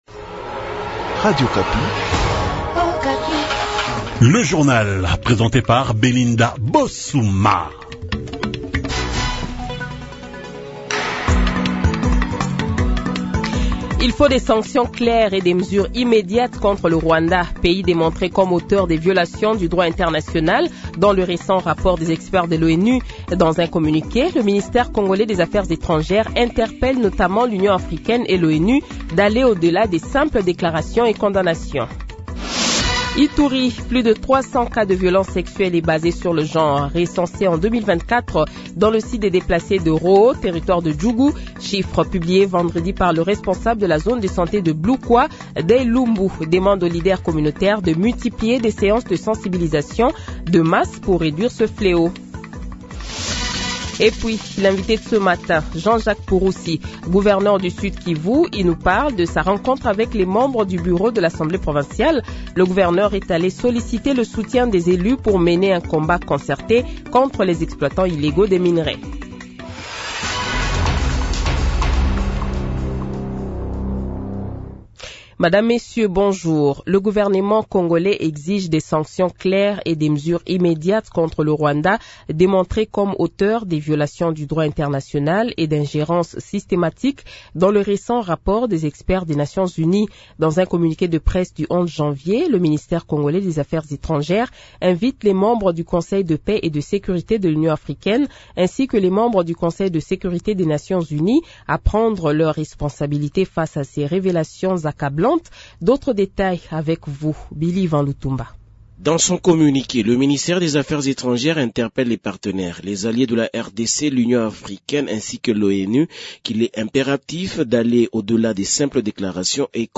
Invité : Jean Jacques Purusi, gouverneur du sud kivu